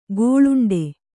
♪ gōḷuṇḍe